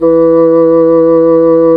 Index of /90_sSampleCDs/Roland LCDP04 Orchestral Winds/WND_Bassoons/WND_Bassoon 4
WND CSSN E3.wav